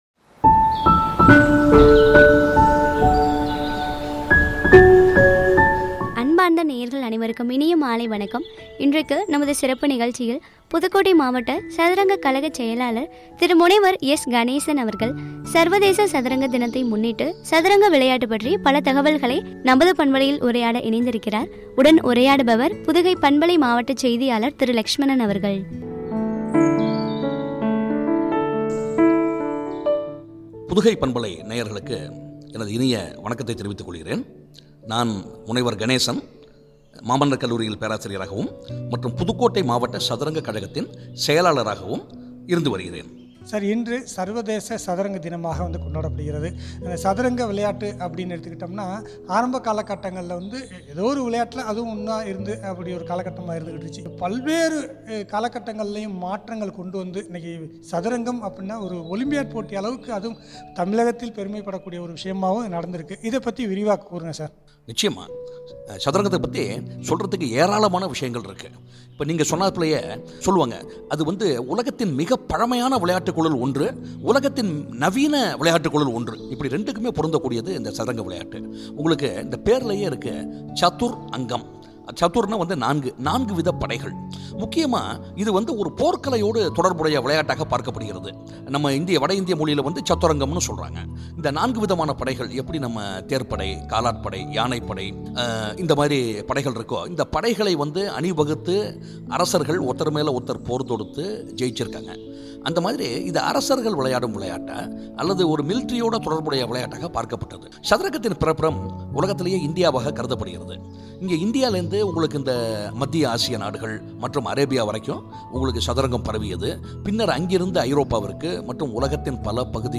சாதிக்கலாம் வாங்க – சதுரங்க விளையாட்டு பற்றிய உரையாடல்.